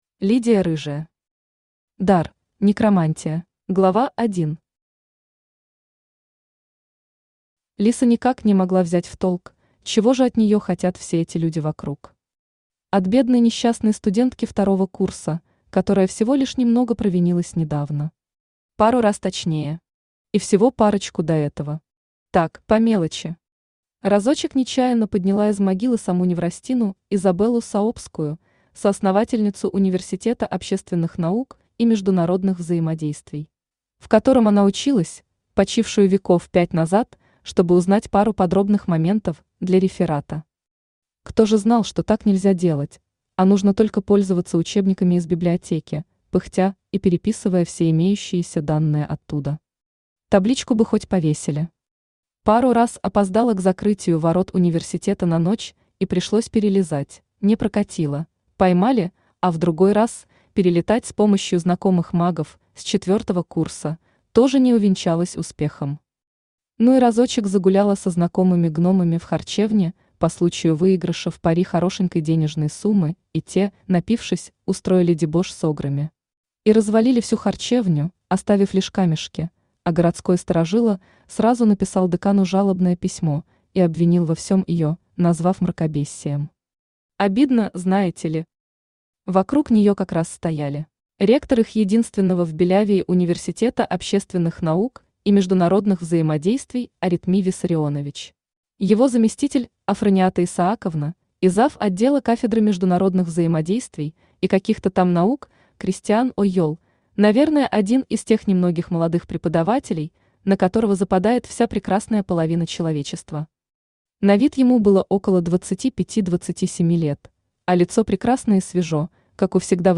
Аудиокнига Дар: некромантия | Библиотека аудиокниг
Aудиокнига Дар: некромантия Автор Лидия Рыжая Читает аудиокнигу Авточтец ЛитРес.